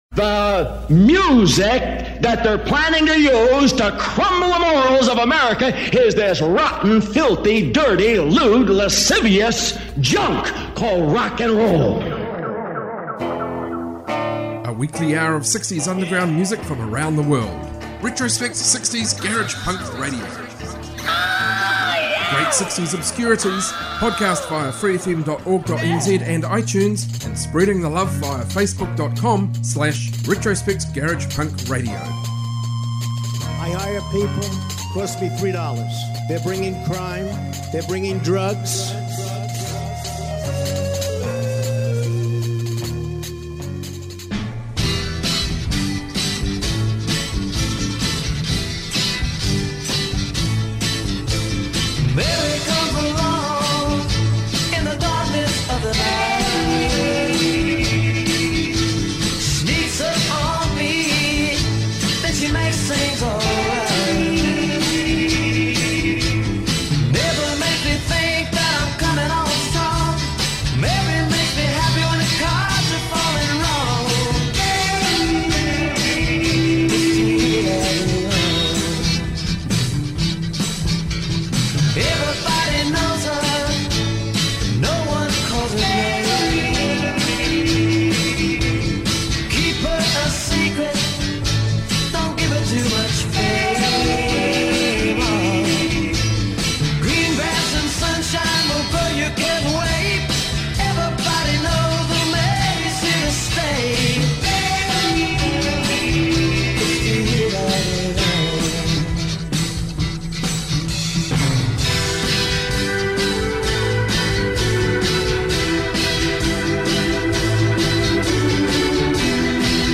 60s global garage rock